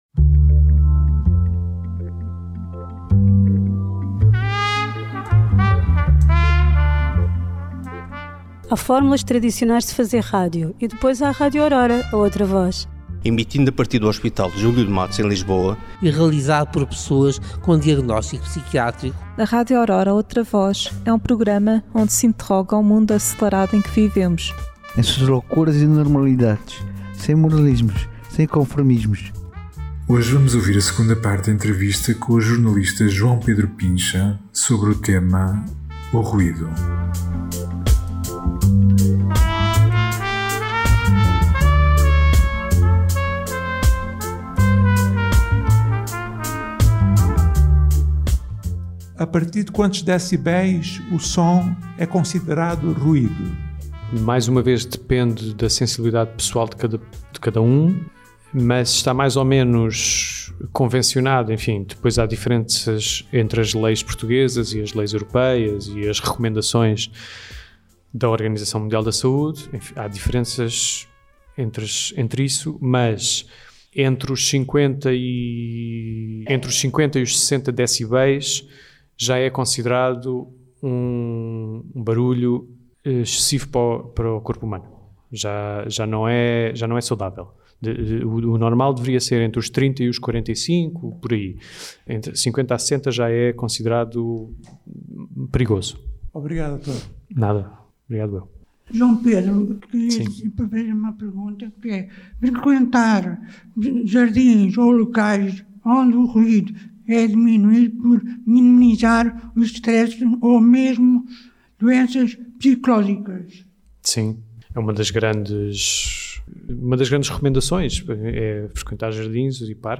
Segunda parte da entrevista